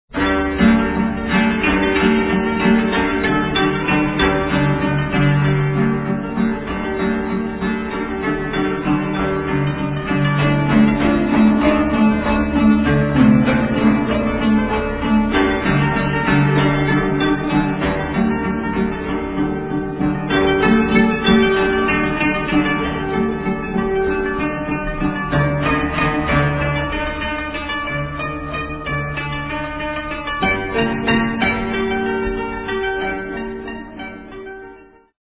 Five kotos, hoteki, shamisen and shakuhachi